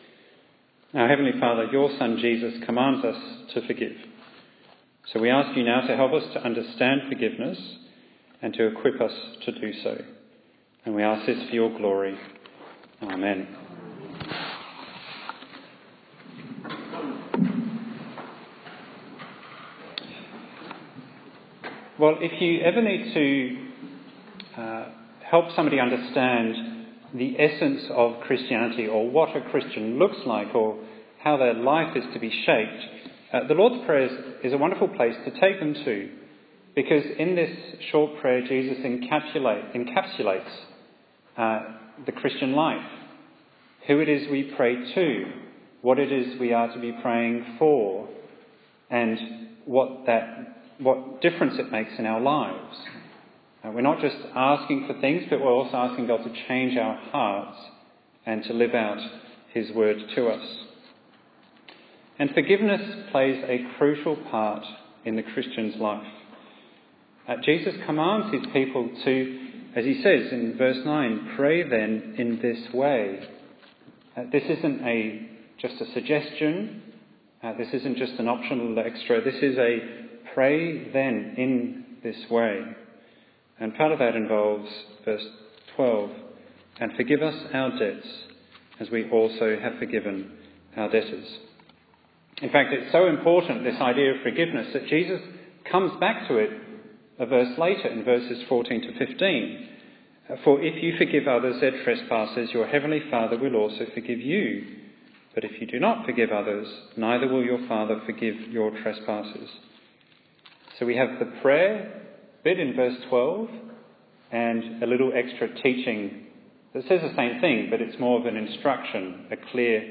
Bible Text: Matthew 6:12, Matthew 18:21-35 | Preacher